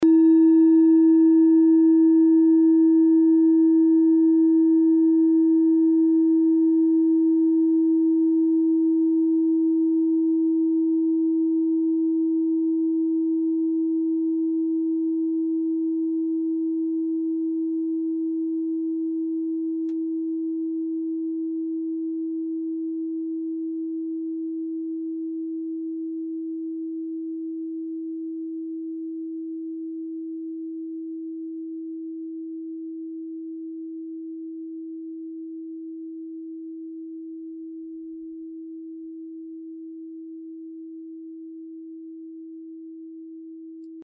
Klangschalen-Typ: Bengalen
Klangschale Nr.7
(Aufgenommen mit dem Filzklöppel/Gummischlegel)
klangschale-set-2-7.mp3